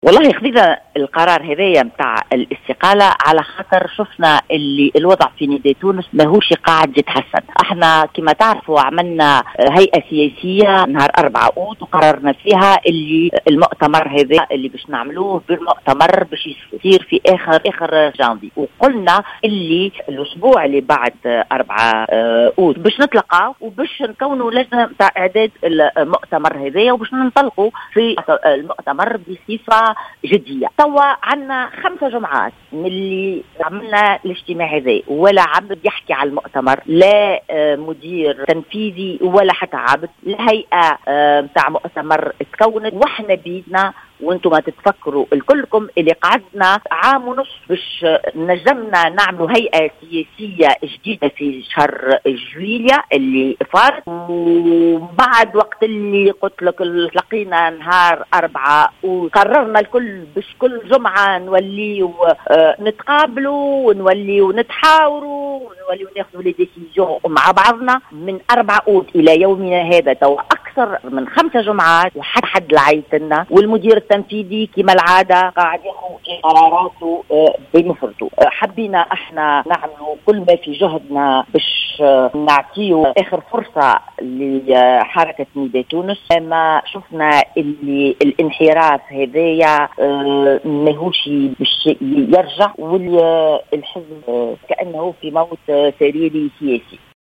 وأوضحت في تصريح لـ "الجوهرة اف أم" ان مرد هذه الاستقالة أيضا هو عدم القيام بأي خطوة في اتجاه الاستعداد لعقد مؤتمر الحزب بعد الاتفاق بشأنه منذ 4 اوت الماضي ورغم تكوين هيئة المؤتمر.